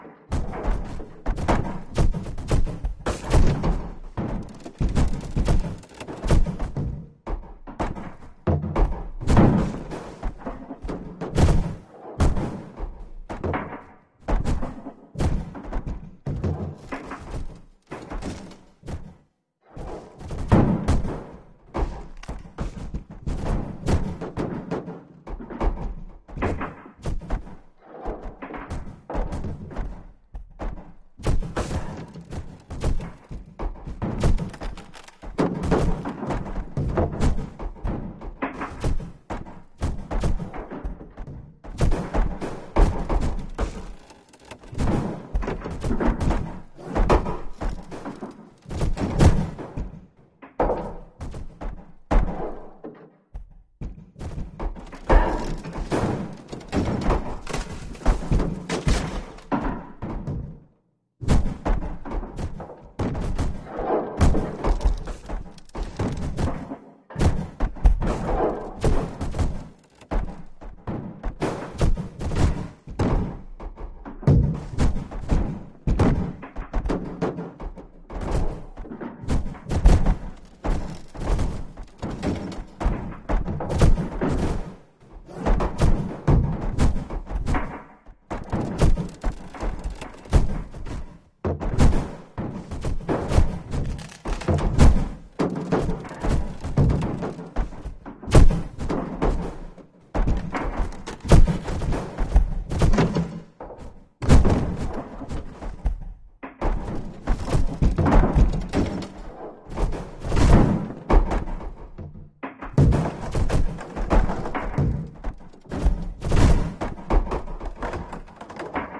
Звуки зомби
Здесь собраны жуткие стоны, агрессивное рычание, скрежет зубов и другие пугающие эффекты, которые помогут создать напряженную обстановку в вашем проекте.
Звук ломающихся дверей под натиском зомби